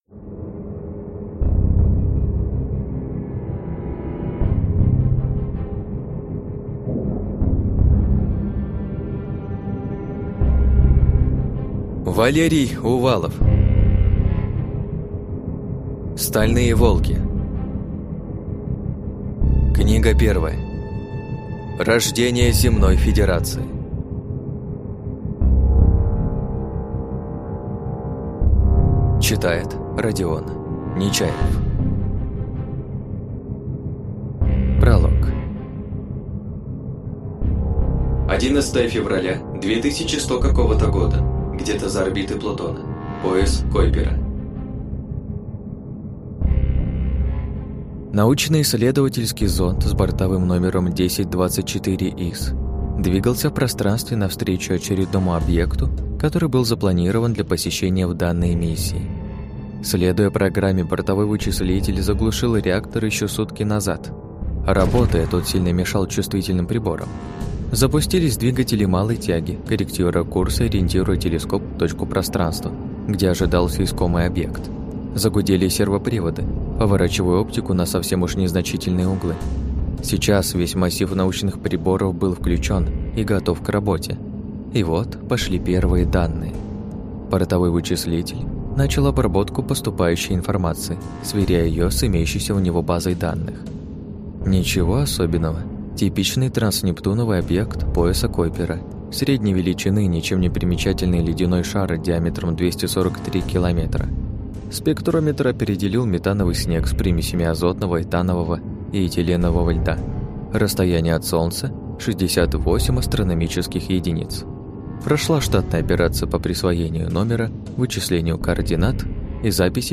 Аудиокнига Стальные Волки. Рождение Земной Федерации | Библиотека аудиокниг